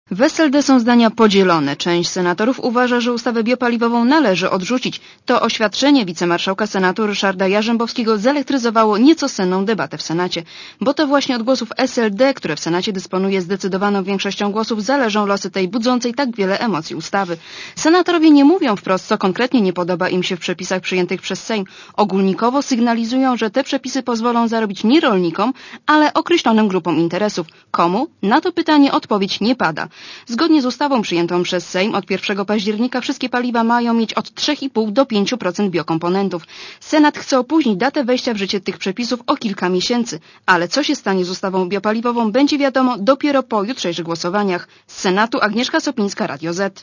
Relacja reporterki Radia Zet (180Kb)